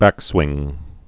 (băkswĭng)